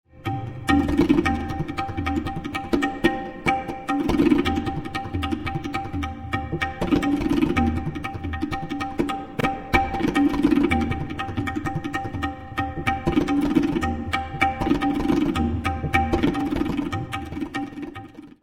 TABLA
Click here and listen to an other small MP3- excerpt and enjoy the different sounds played on the tabla surface.